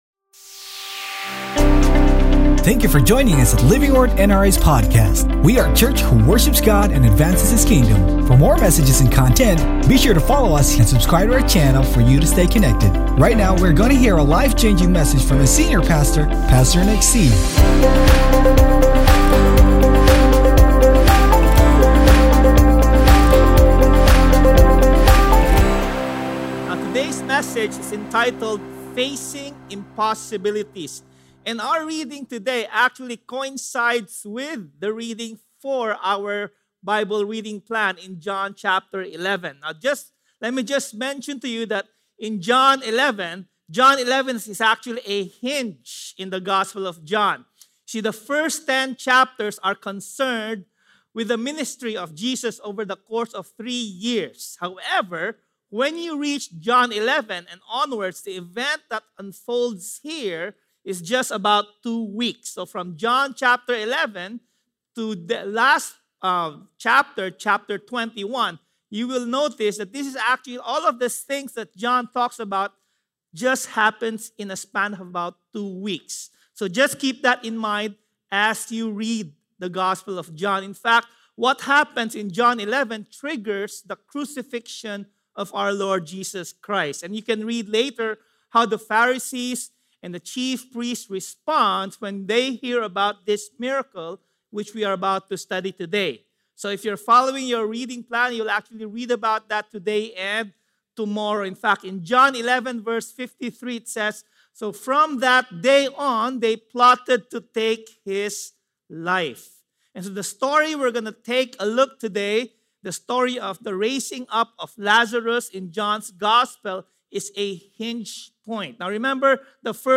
Sermon Title: FACING IMPOSSIBILITIES Scripture Text: JOHN 11:1-4 Sermon Notes: JOHN 11:53 NIV 53 So from that day on they plotted to take his life.